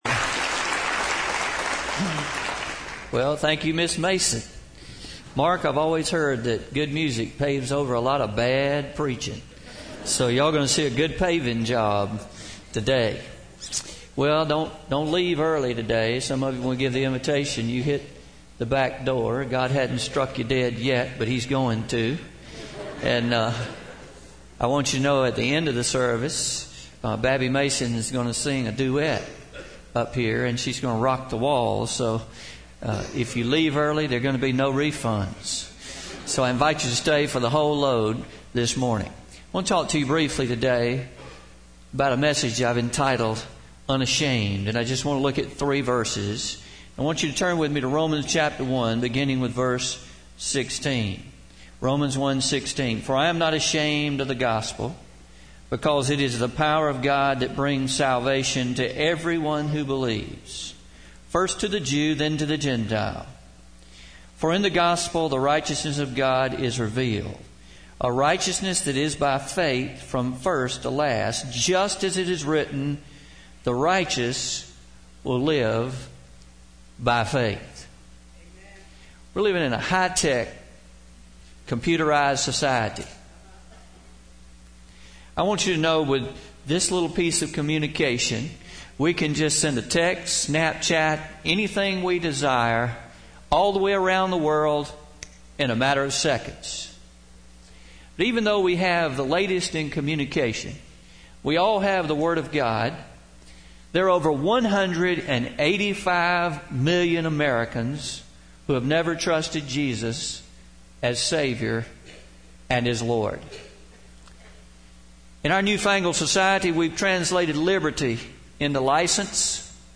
11-13-16am Sermon – Unashamed